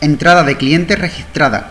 card_acceso_permitido_entrada.wav